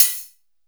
SouthSide Hi-Hat (12).wav